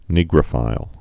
(nēgrə-fīl)